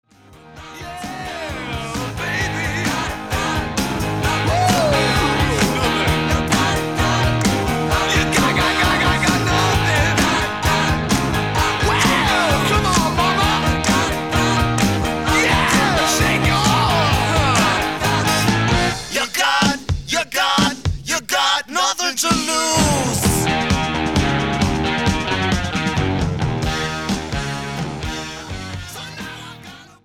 Recorded Location:Bell Sound Studios, New York City
Genre:Hard Rock, Heavy Metal